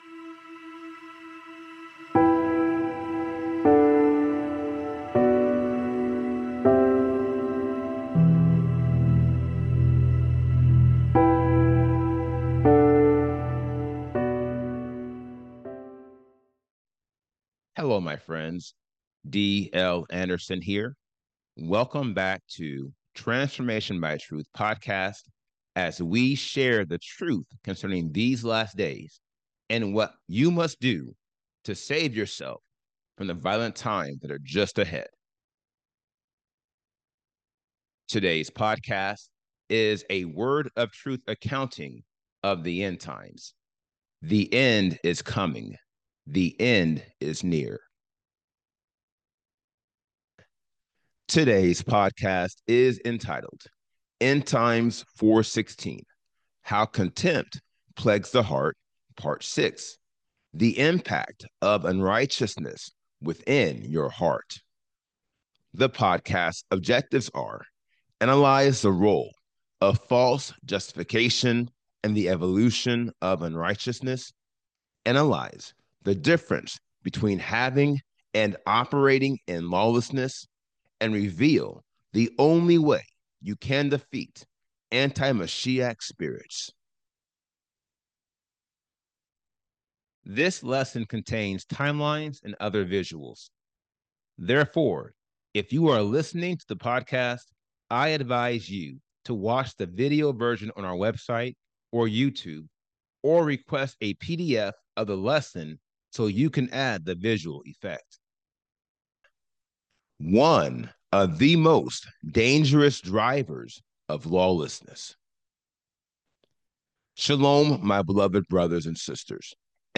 This podcast is a 400-level lecture dedicated to analyzing the 80 degrees of lawlessness and showing you how you can eliminate each one to obtain the Seal of Elohim. Its purpose is to analyze the role of false justification in the evolution of unrighteousness, analyze the difference between having and operating in lawlessness, and reveal the only way you can defeat anti-Mashiach spirits.